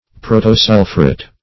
Meaning of protosulphuret. protosulphuret synonyms, pronunciation, spelling and more from Free Dictionary.
Search Result for " protosulphuret" : The Collaborative International Dictionary of English v.0.48: Protosulphuret \Pro`to*sul"phu*ret\, n. [Proto- + sulphuret.]